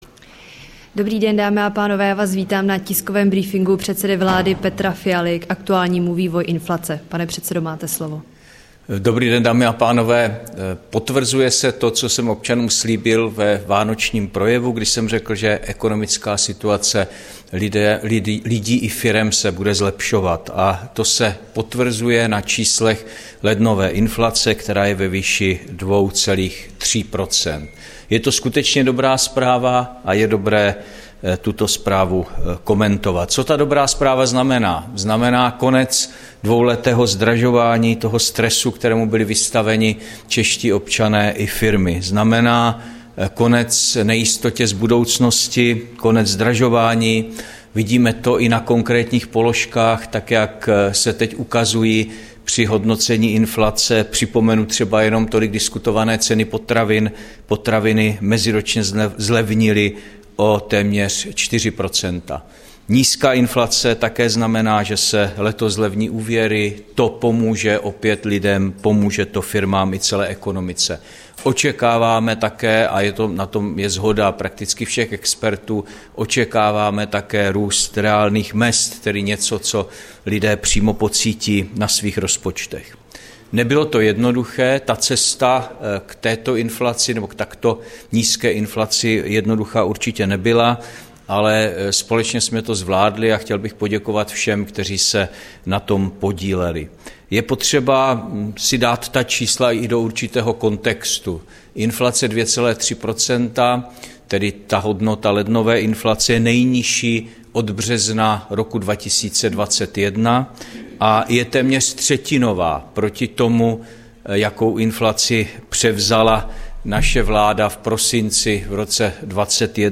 Brífink předsedy vlády Petra Fialy k vývoji inflace, 15. února 2024